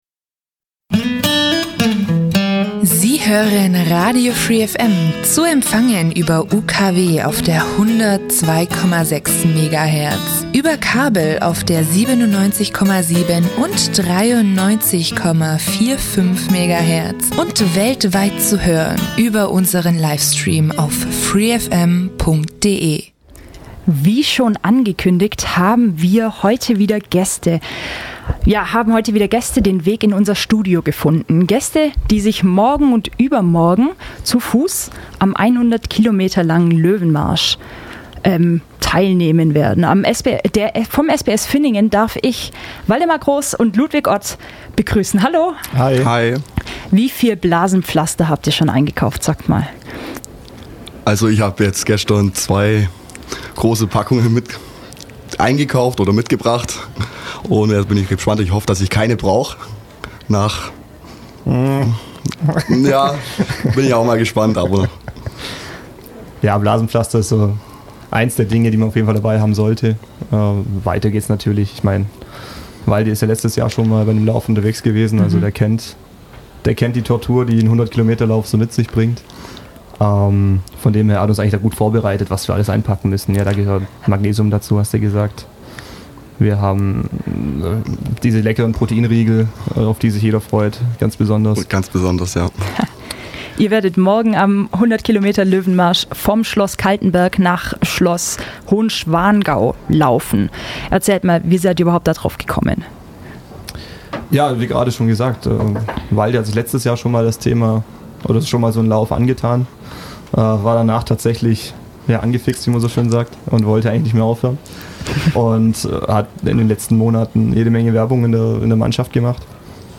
Ein Mitschnitt aus dem Live-Interview findet ihr im Potcast